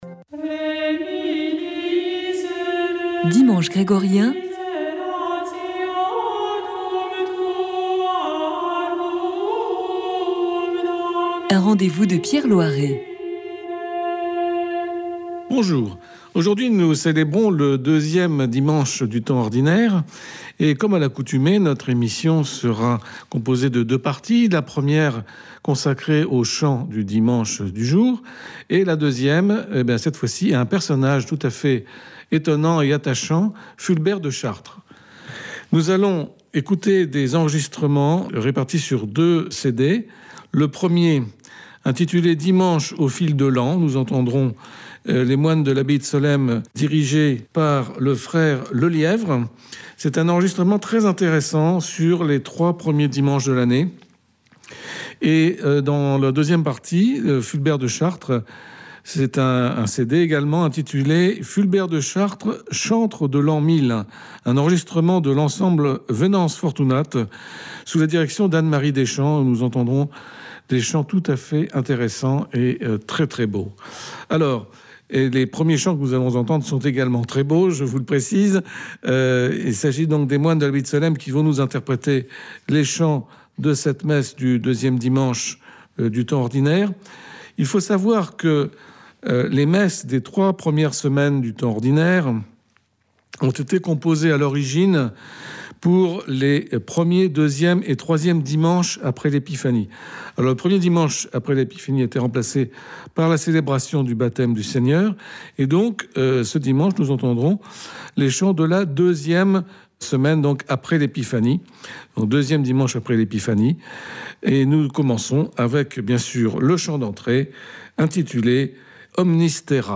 Dimanche grégorien